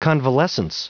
Prononciation du mot convalescence en anglais (fichier audio)